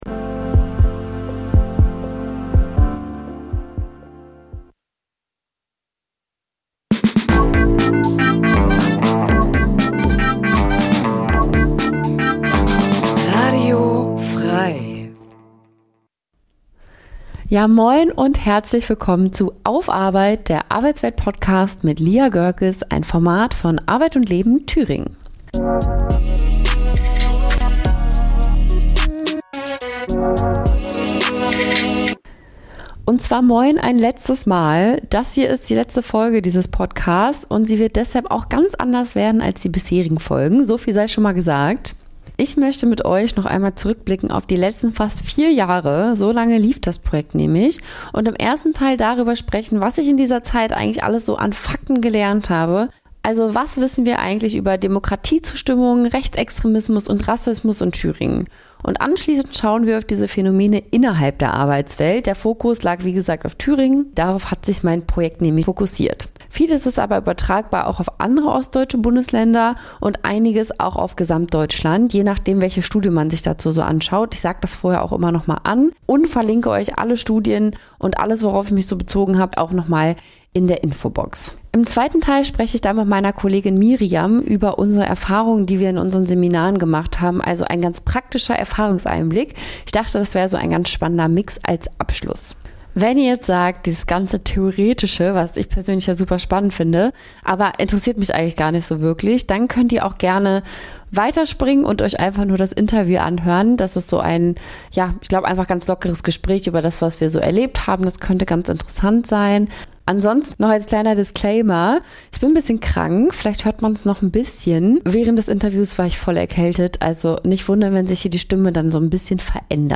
Expterten zu diesen Themen befragen.